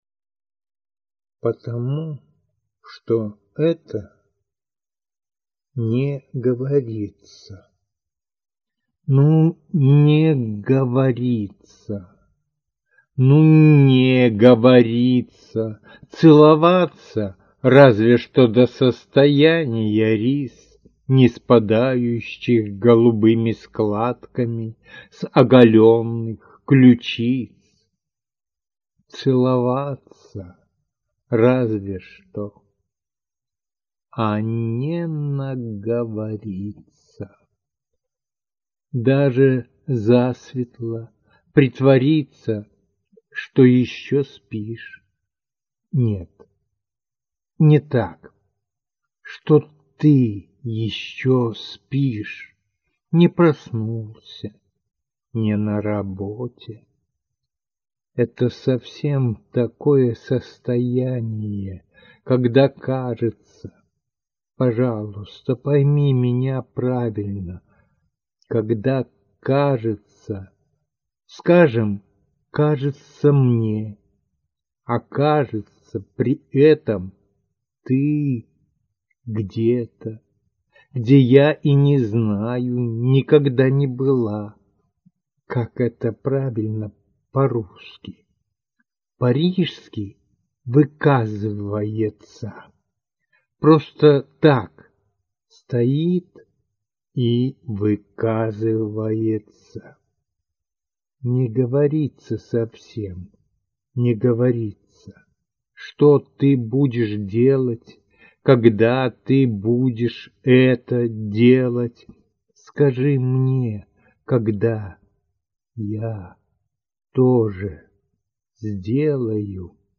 звучащие стихи
для тех, кто слушает стихи